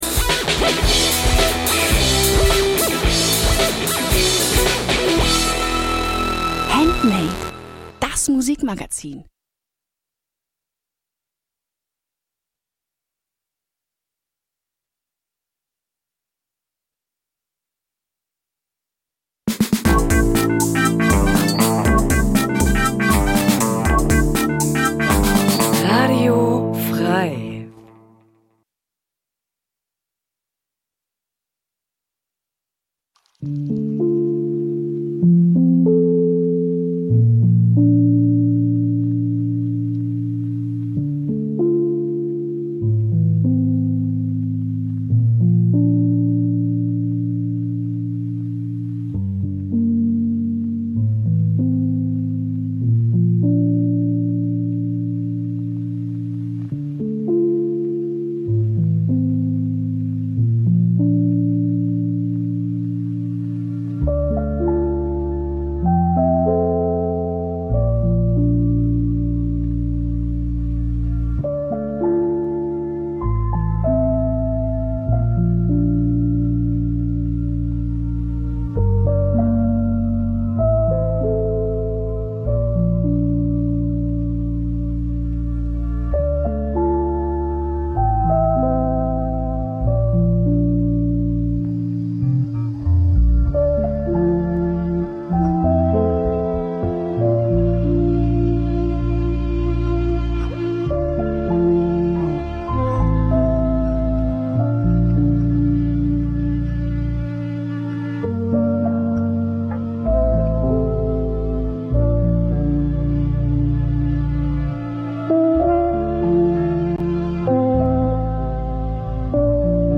Musiksendung Dein Browser kann kein HTML5-Audio.